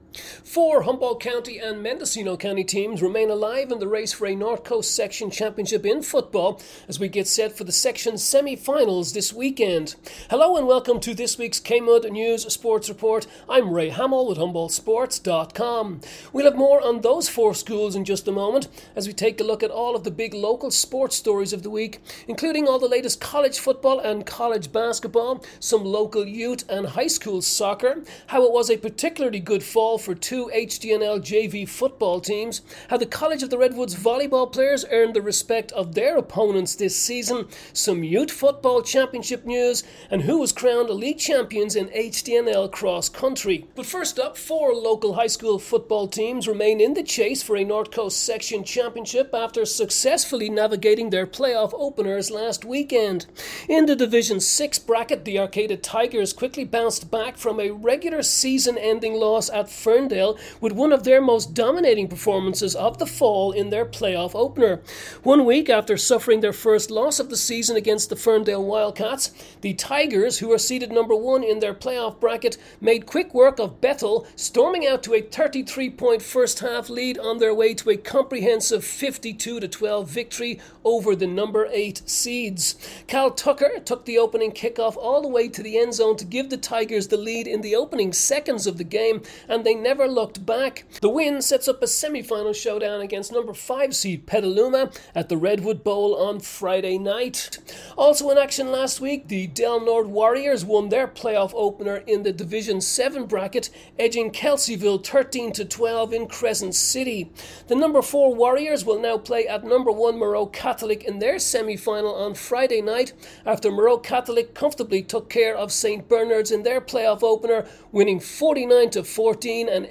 Nov 21 KMUD Sports Report